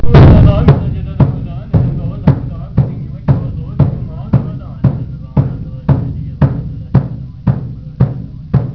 A monk chants the day's prayers